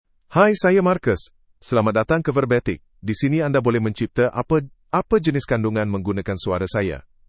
MaleMalayalam (India)
MarcusMale Malayalam AI voice
Voice sample
Listen to Marcus's male Malayalam voice.
Marcus delivers clear pronunciation with authentic India Malayalam intonation, making your content sound professionally produced.